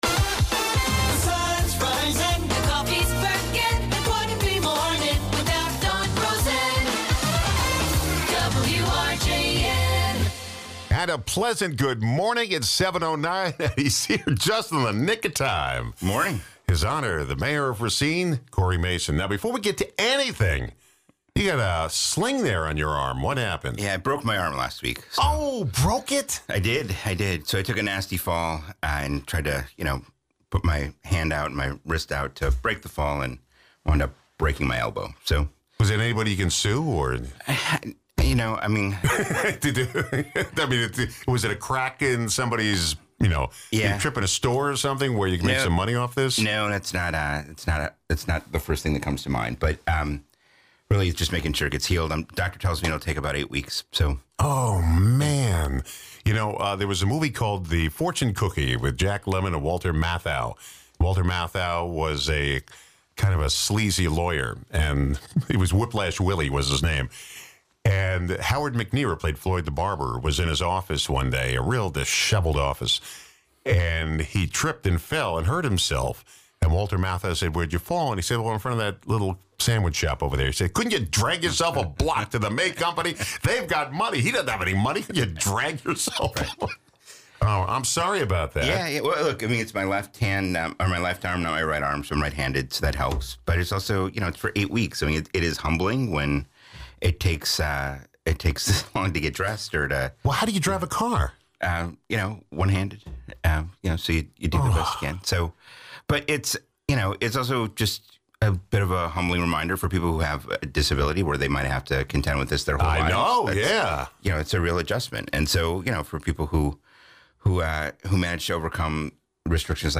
Guests: Cory Mason